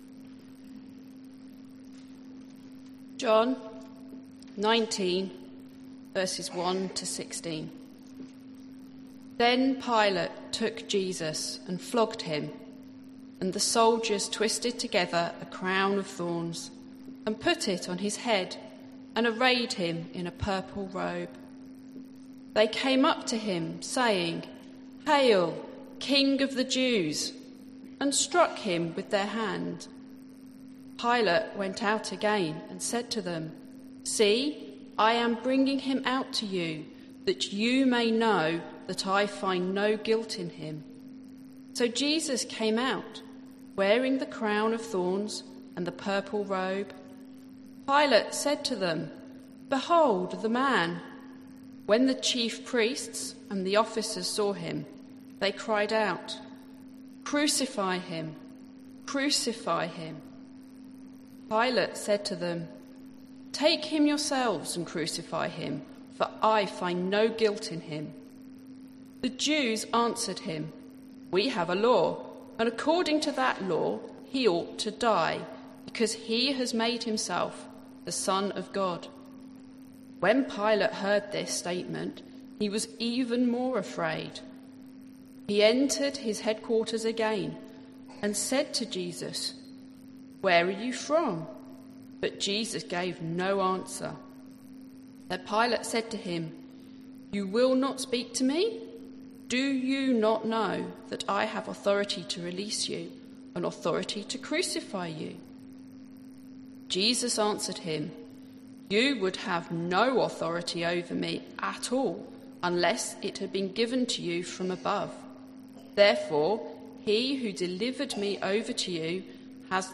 Holy Week - Good Friday - It Is Finished - Hereford Baptist Church – Spirit, Ministry, Mission
Sermon